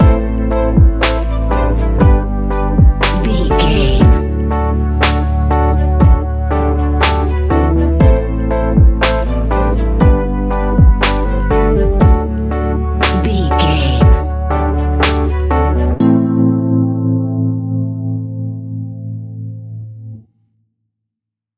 Ionian/Major
D♭
chilled
laid back
sparse
new age
chilled electronica
ambient
atmospheric